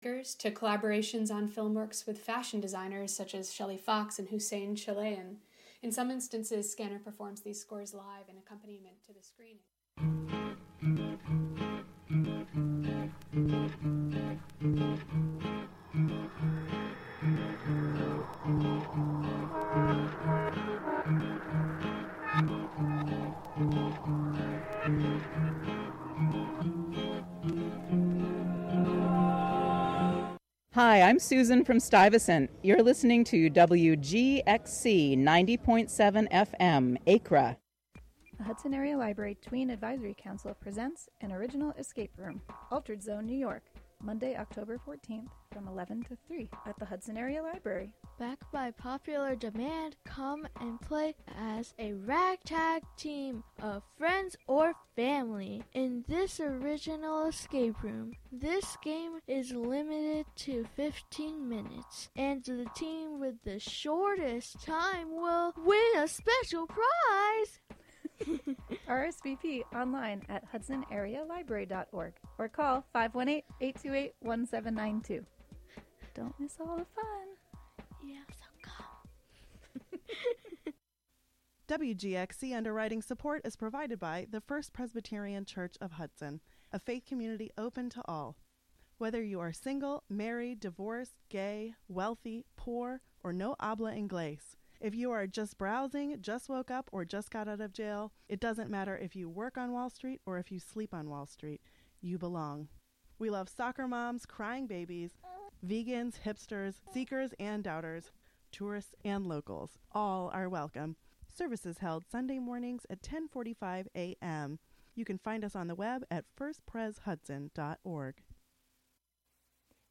Monthly program featuring music and interviews from Dutchess County resident.